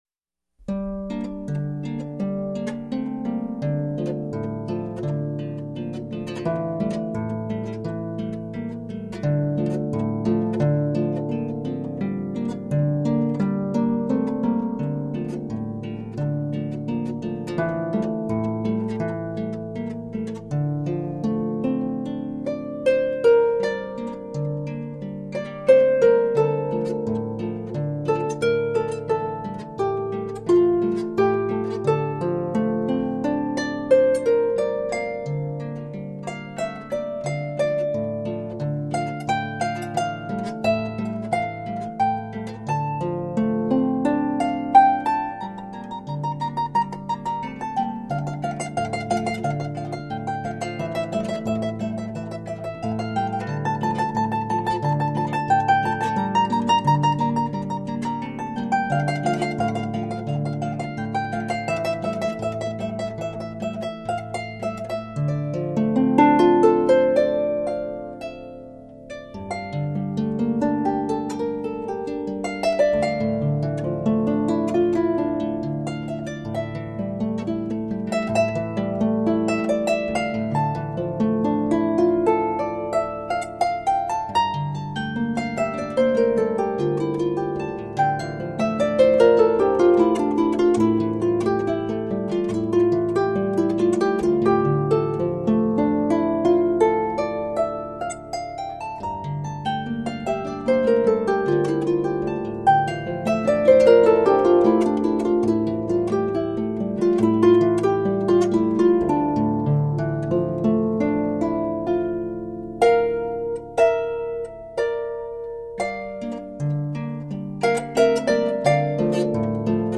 My own Canadian Composition with Russian
and  Spanish overtones!
Solo Celtic Harp